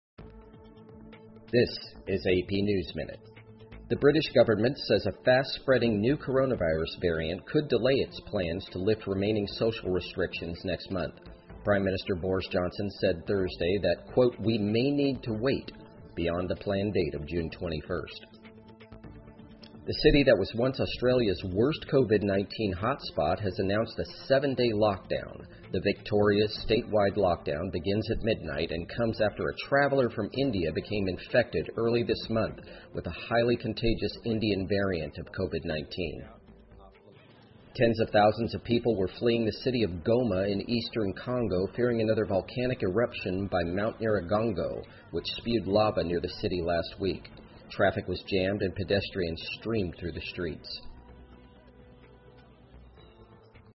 美联社新闻一分钟 AP 印度变种或推迟英国解封计划 听力文件下载—在线英语听力室